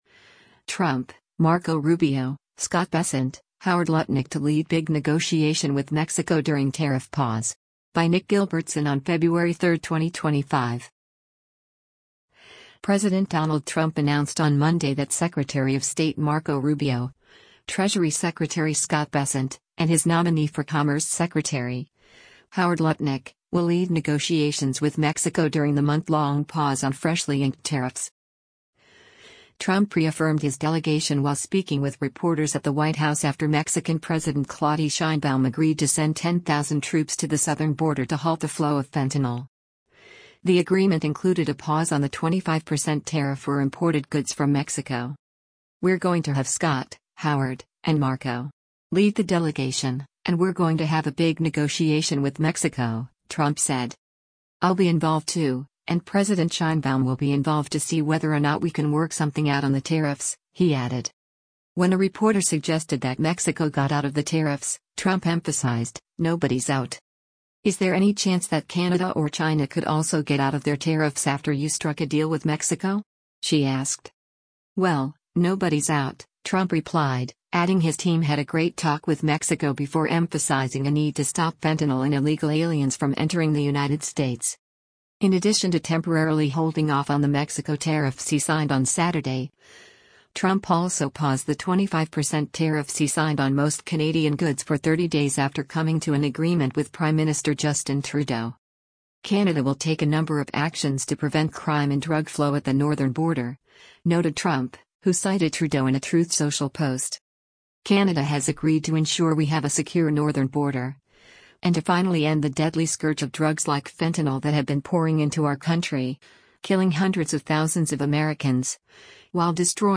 Trump reaffirmed his delegation while speaking with reporters at the White House after Mexican President Claudi Sheinbaum agreed to send 10,000 troops to the southern border to halt the flow of fentanyl.